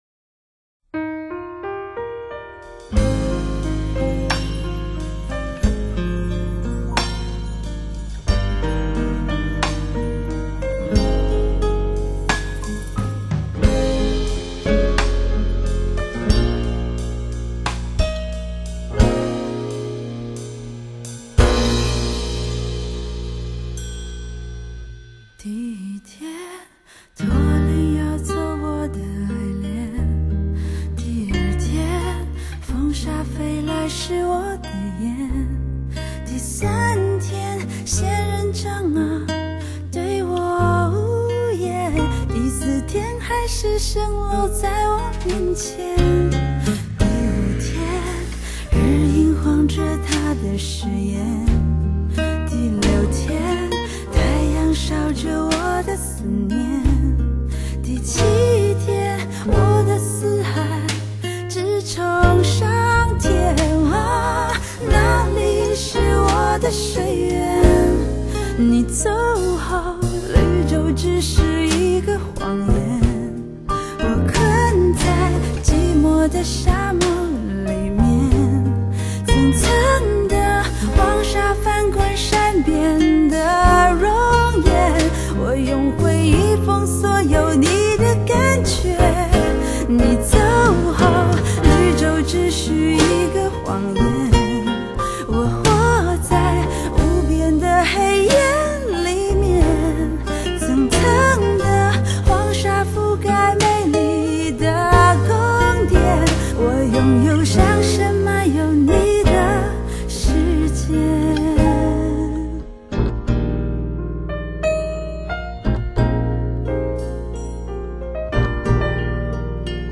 许多离愁和忧伤的成分。